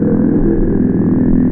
Low_Rumble03.wav